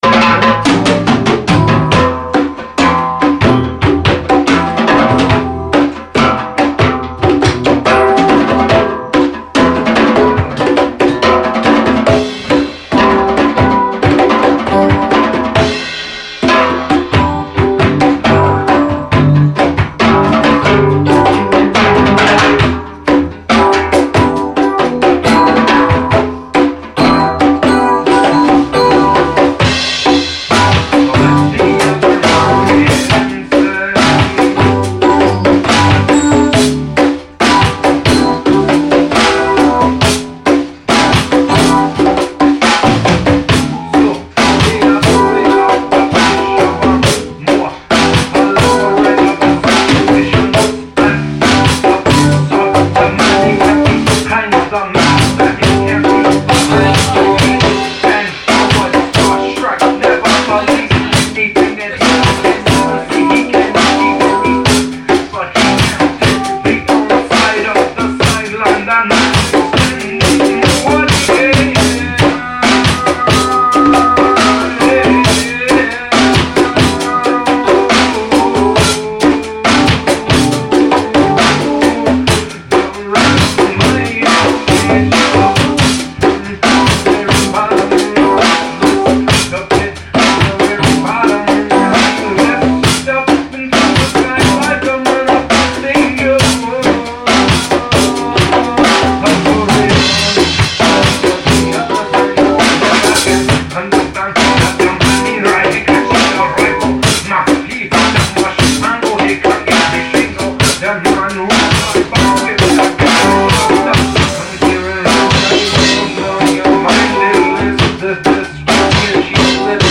Drums
Keyboards
Hand Drums
Bass
Vocal Accompaniment